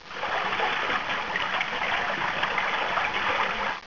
wasserrauschen_bach.wav